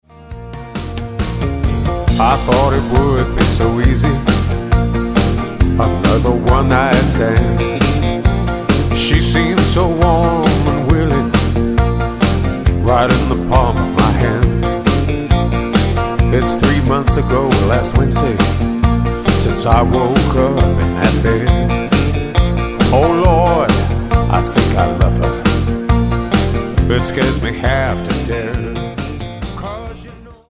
Tonart:Bb Multifile (kein Sofortdownload.
Die besten Playbacks Instrumentals und Karaoke Versionen .